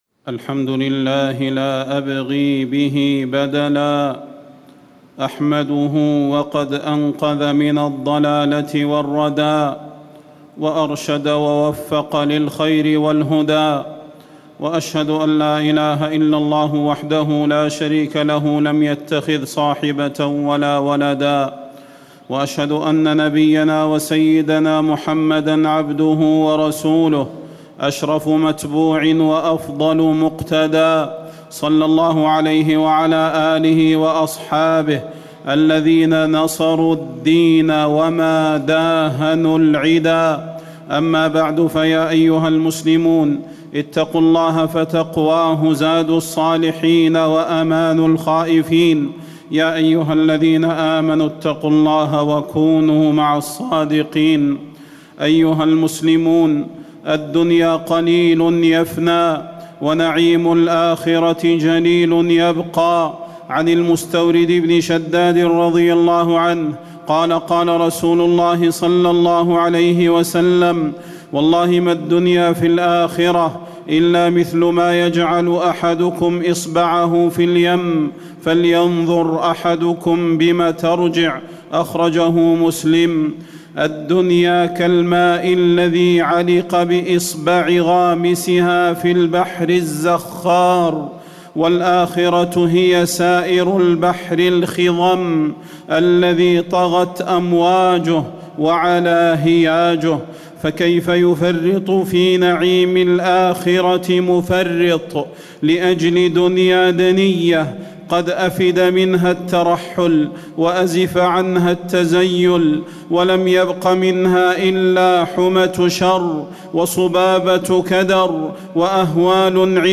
تاريخ النشر ١٢ ذو القعدة ١٤٣٨ هـ المكان: المسجد النبوي الشيخ: فضيلة الشيخ د. صلاح بن محمد البدير فضيلة الشيخ د. صلاح بن محمد البدير الدنيا الفانية والآخرة الباقية